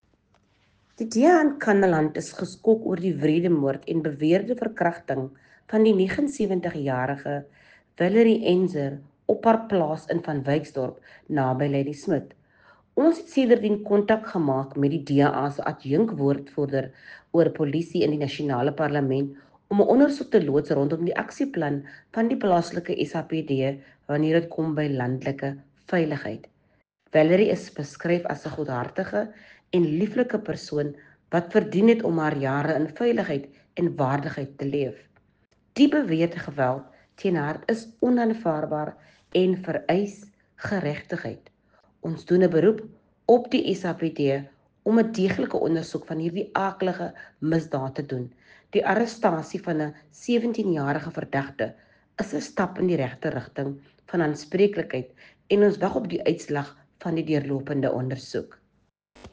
Note to editors: Please find attached soundbites in English and